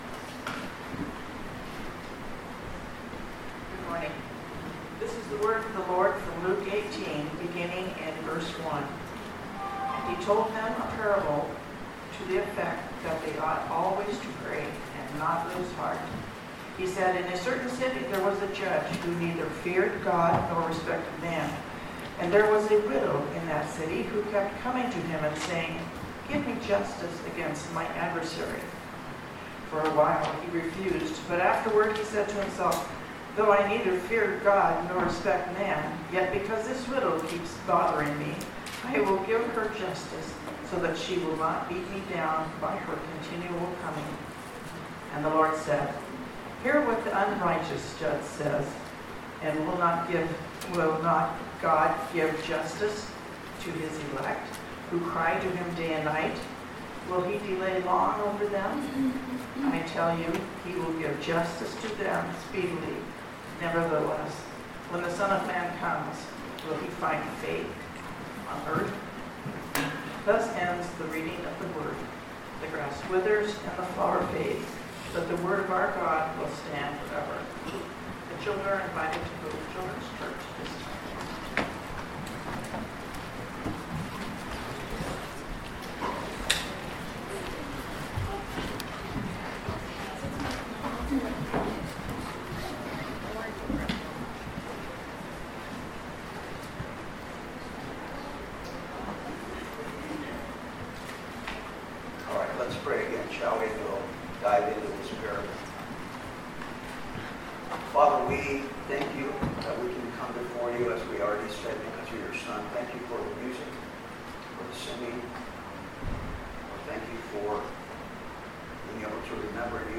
Passage: Luke 18:1-8 Service Type: Sunday Morning